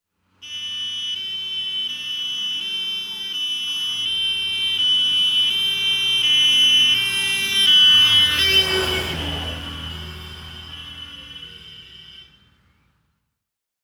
transport
Fire Truck Siren German 1